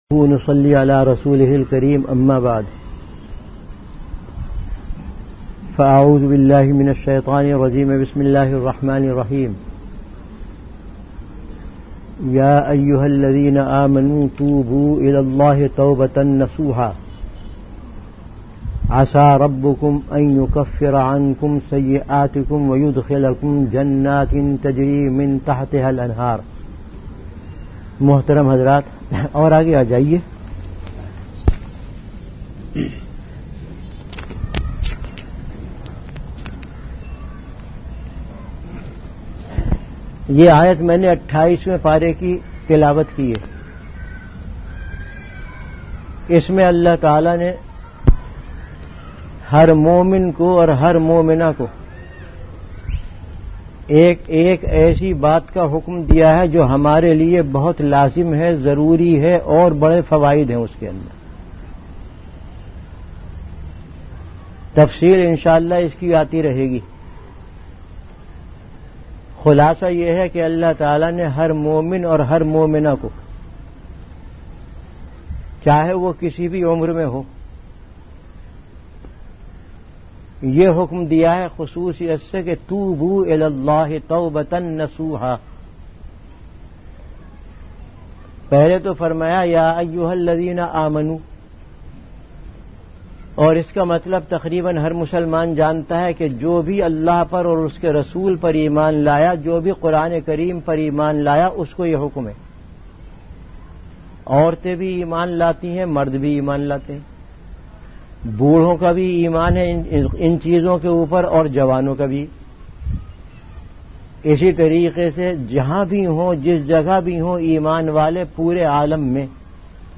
Delivered at Jamia Masjid Bait-ul-Mukkaram, Karachi.
Bayanat · Jamia Masjid Bait-ul-Mukkaram, Karachi
Event / Time Before Juma Prayer